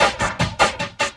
45LOOP SD2-R.wav